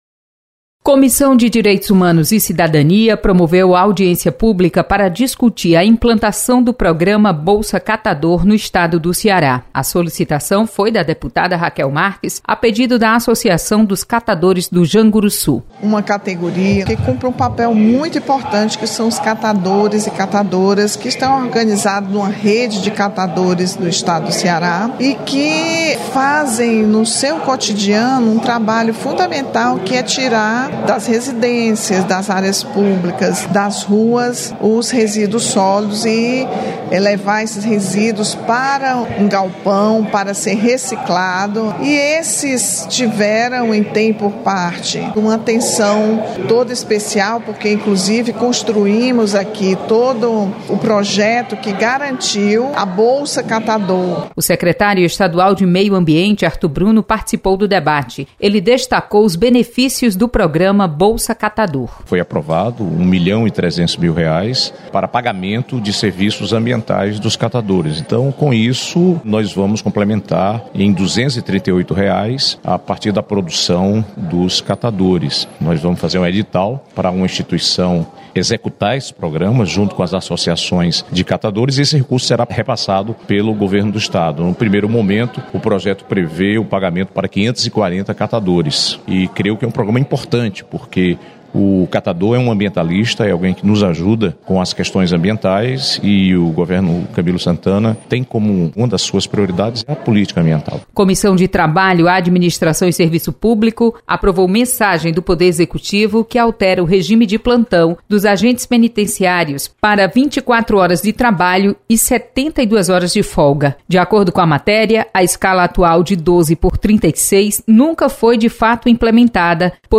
Acompanhe resumo das comissões técnicas permanentes da Assembleia Legislativa com a repórter